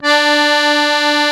MUSETTE 1 .5.wav